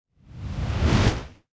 fly_off.wav